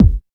28 KICK 3.wav